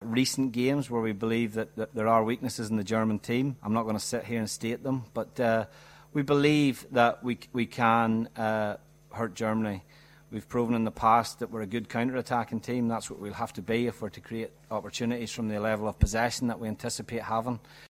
تحدث مدرب منتخب أيرلندا الشمالية مايكل أونيل في الندورة الصحفية عن مواجهة منتخب ألمانيا مشيرا أن فريقه يملك الإمكانات اللازمة لتحقيق نتيجة إيجابية أمام ألمانيا التي يرى أن لها نقاط ضعف في ختام منافسات المجموعة الثالثة ببطولة أوروبا لكرة القدم.